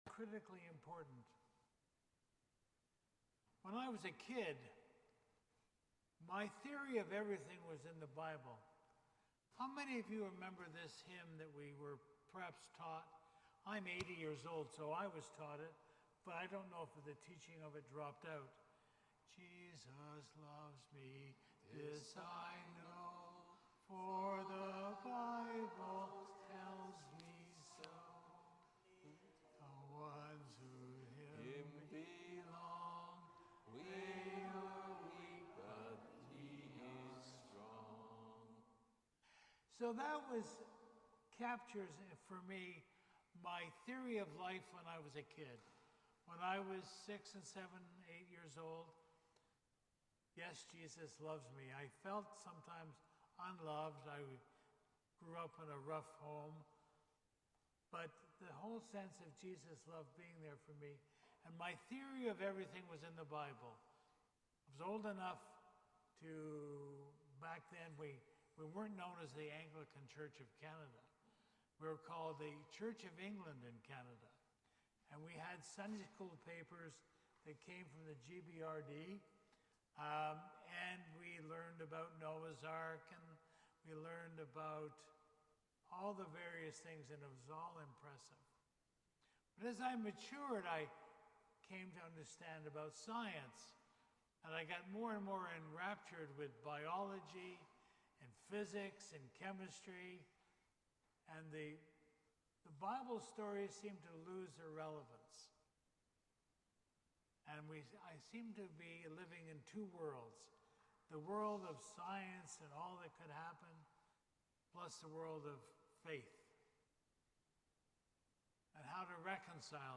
Sermons | St. Dunstan's Anglican